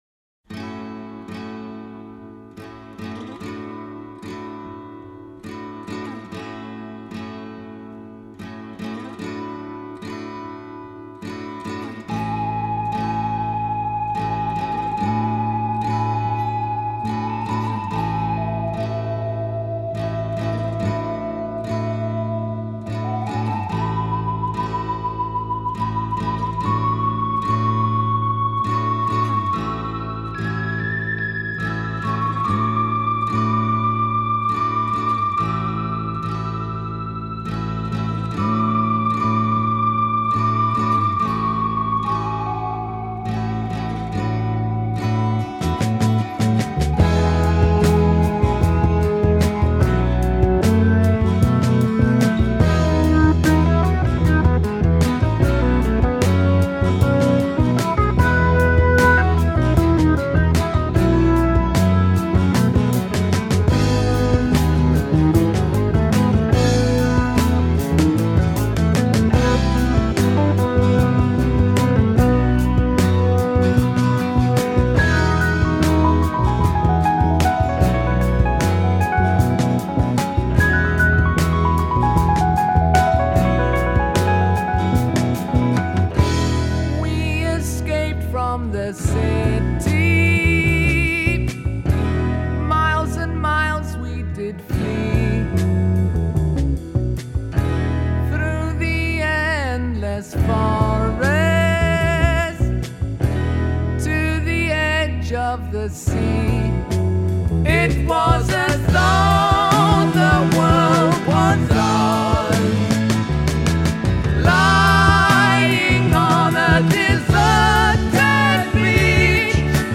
гораздо спокойнее и лиричнее
красивыми пассажами фортепьяно и расслабленной